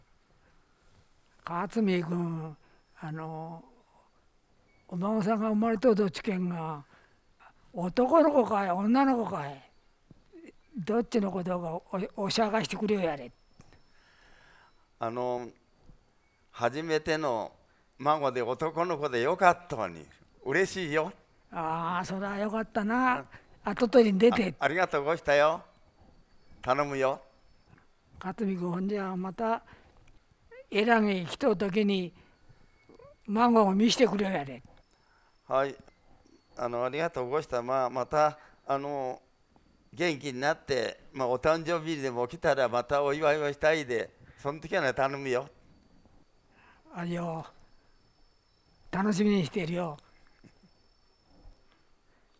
早川町のことば：雨畑・茂倉・奈良田の音声資料集|いずこに
会話（ロールプレイ） ─雨畑─
[2] おしゃーがして：「が」は破裂音～摩擦音 [g～ɣ]。